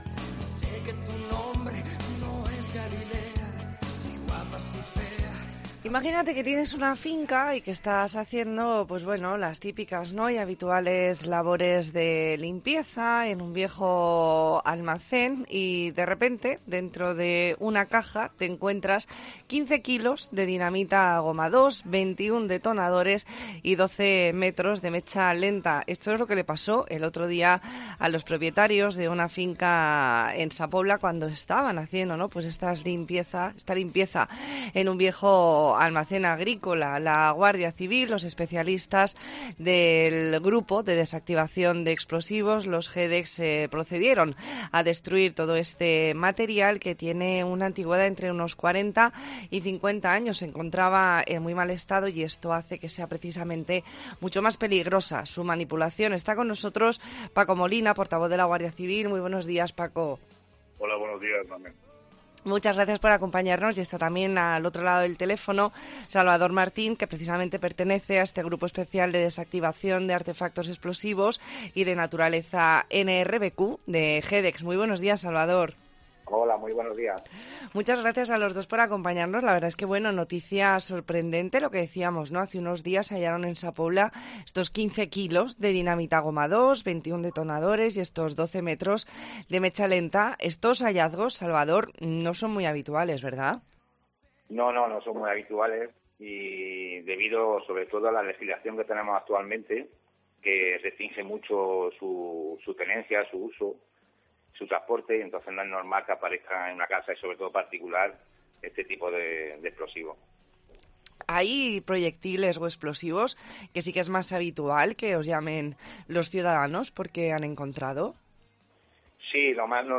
Entrevista en La Mañana en COPE Más Mallorca, jueves 6 de octubre de 2022.